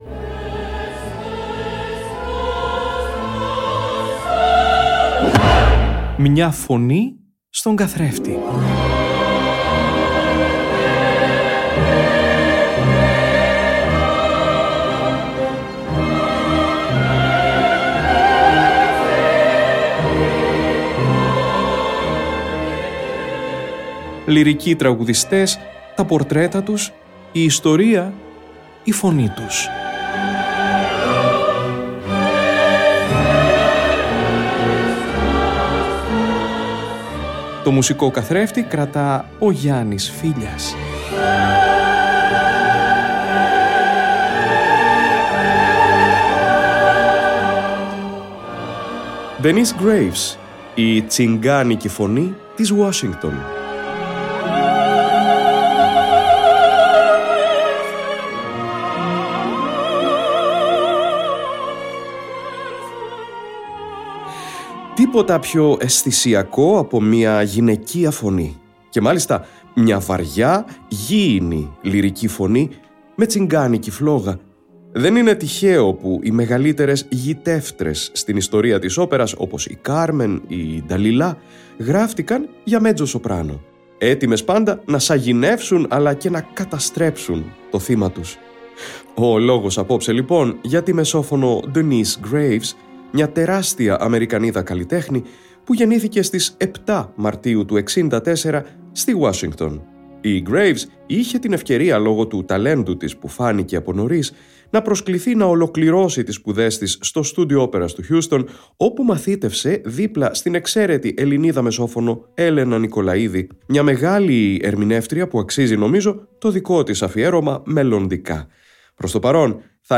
Μια μεστή, γήινη λυρική φωνή, με τσιγγάνικη φλόγα.
Στον «Καθρέφτη» μας αυτή την Παρασκευή 7 Μαρτίου, η μεσόφωνος Denyce Graves.